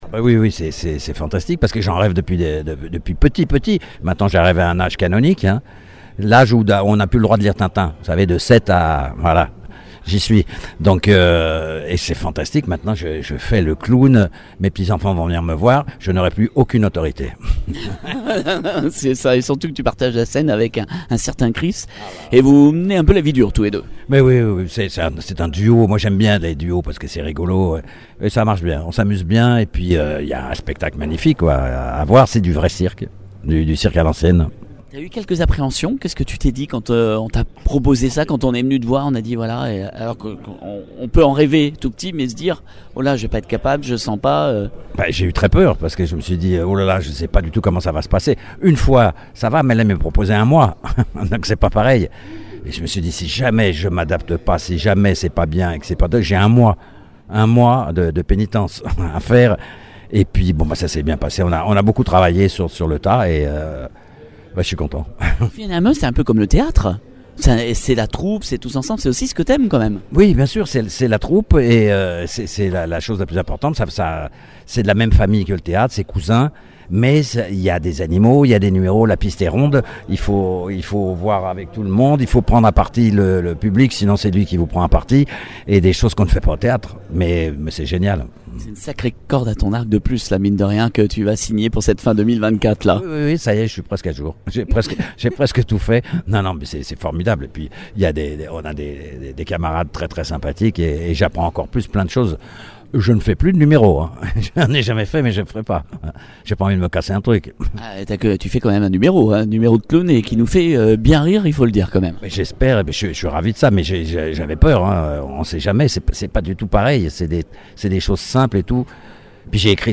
Je vous propose de l’écouter.